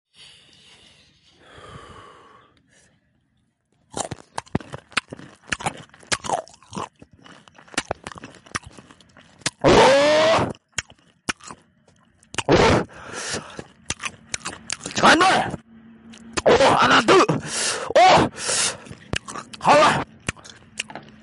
mukbang đá 🥵 sound effects free download